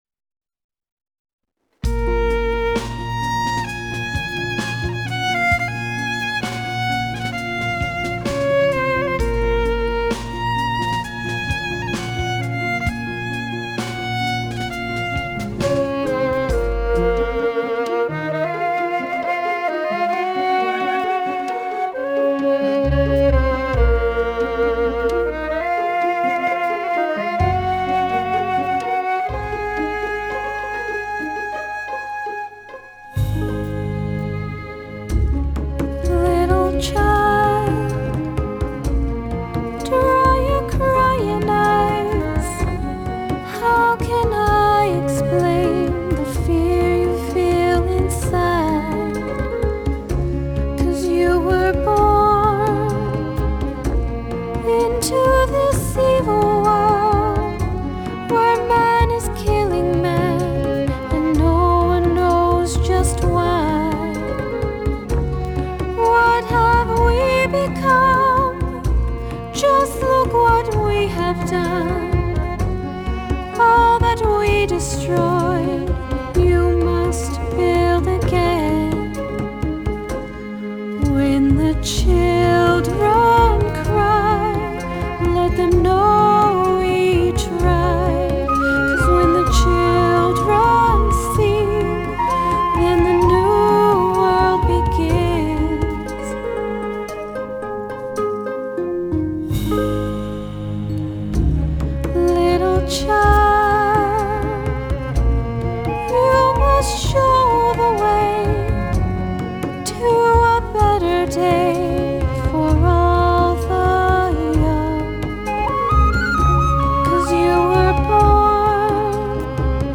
Genre: Electronic, Classical Crossover, Singer-SAongwriter